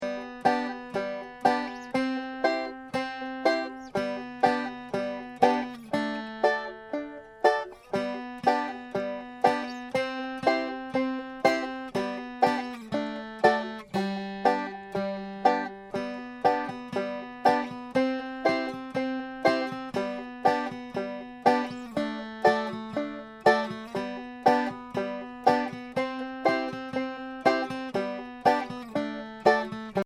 Voicing: Banjo Method